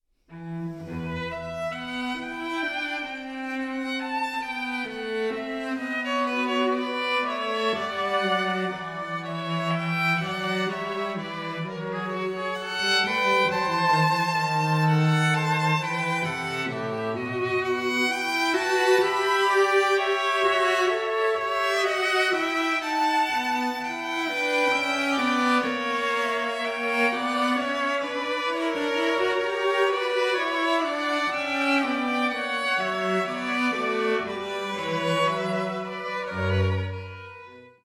36 Sinfonia 7 - E minor